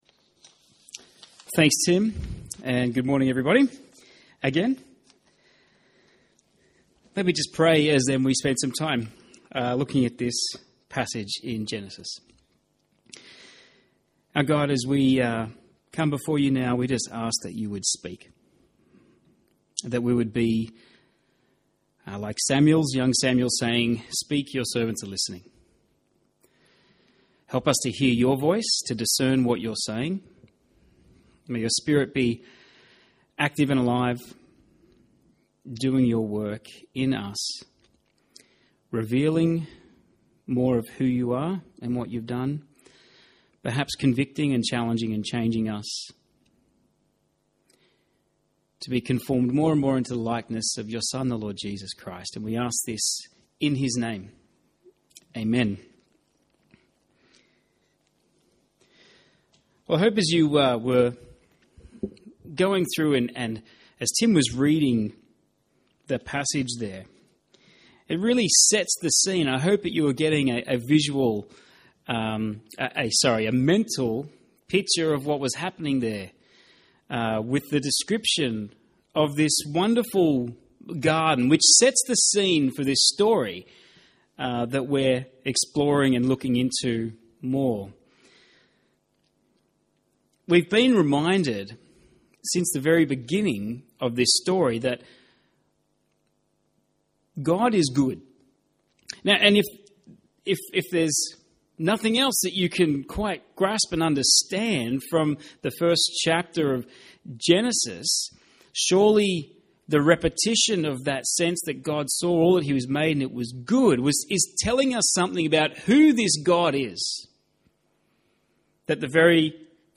by admin | Oct 3, 2021 | Genesis 1-3, Sermons 2021